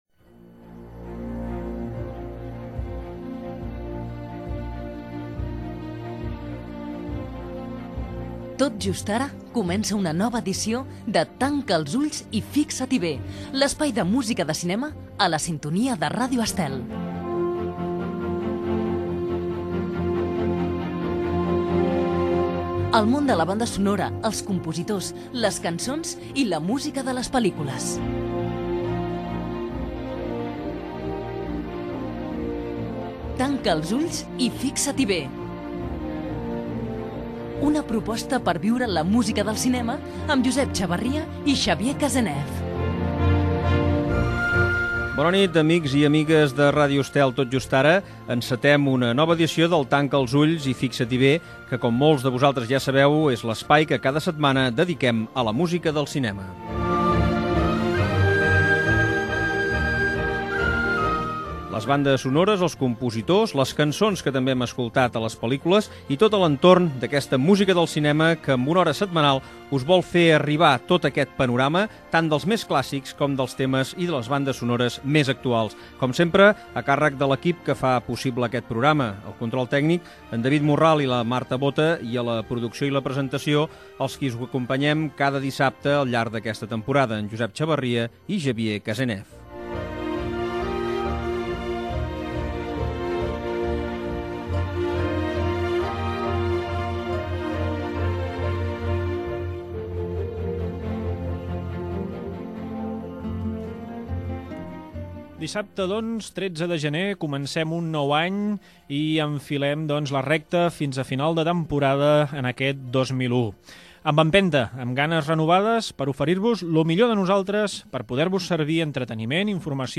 Careta del programa, presentació, equip, sumari, "Cançons amb història" dedicada a "Tal como éramos"
Musical